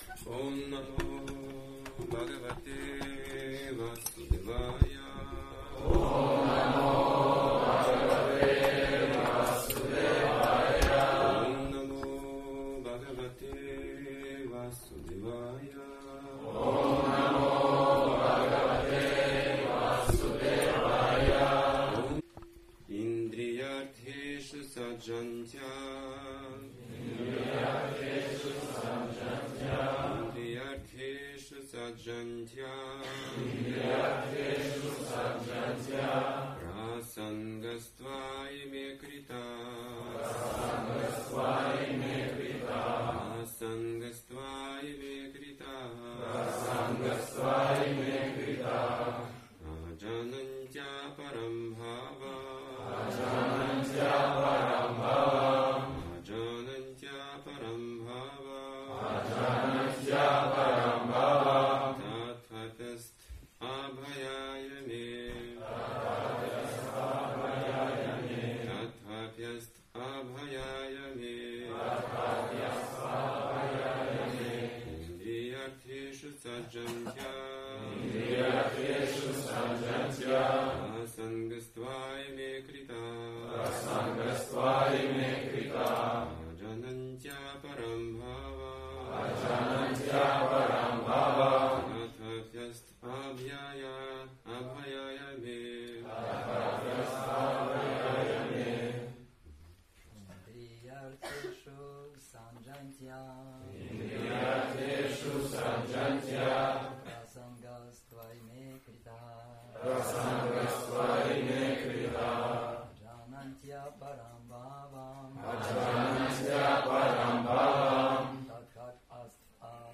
Темы, затронутые в лекции: Как живое существо покрывается иллюзией.